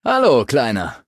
Maleadult01default_convandale_hello_0002ec9d.ogg (OGG-Mediendatei, Dateigröße: 12 KB.